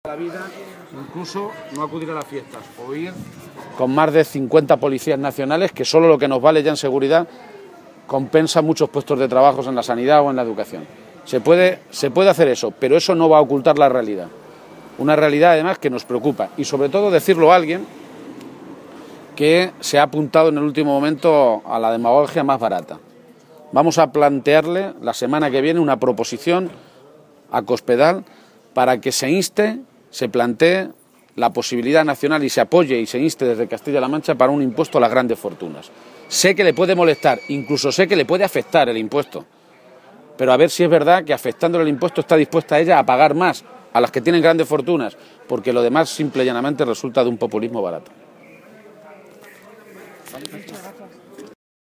García-Page adelantaba esta noticia a preguntas de los medios de comunicación durante su visita a la localidad conquense de Tarancón con motivo de sus fiestas patronales en honor de la Virgen de Riánsares.